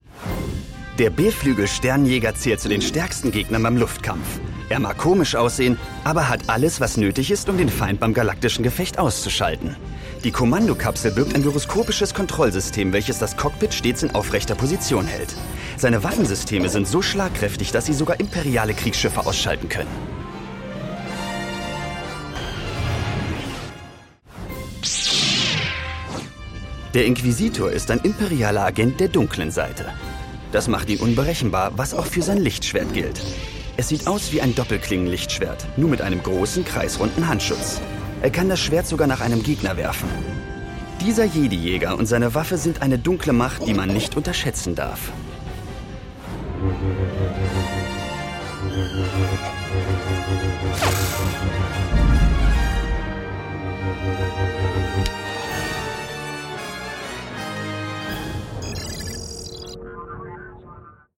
markant, plakativ, sehr variabel
Voice Over